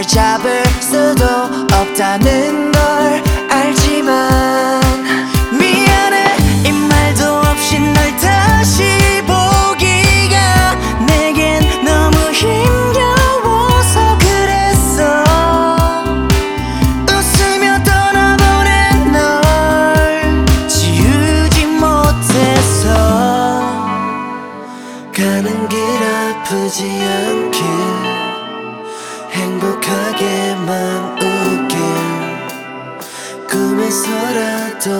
Скачать припев
K-Pop
2020-02-06 Жанр: Поп музыка Длительность